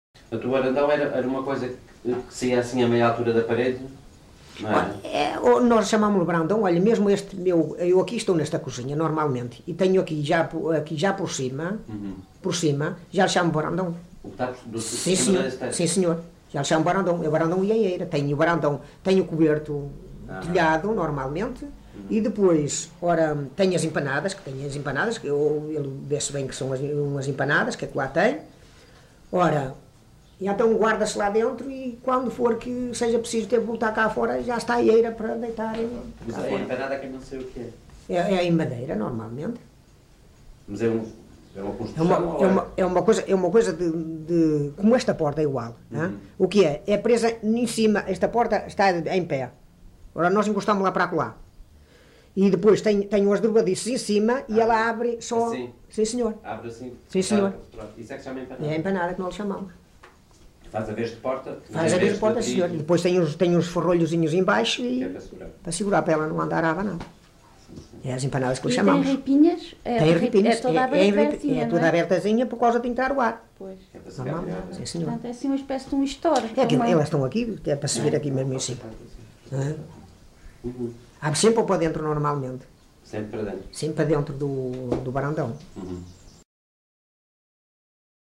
LocalidadeFiscal (Amares, Braga)